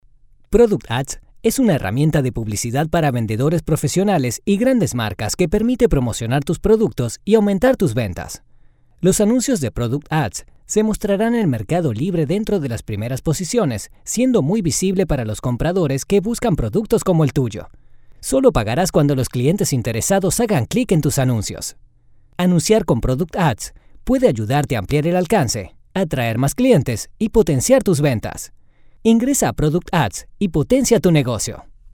male | South American | Standard | adult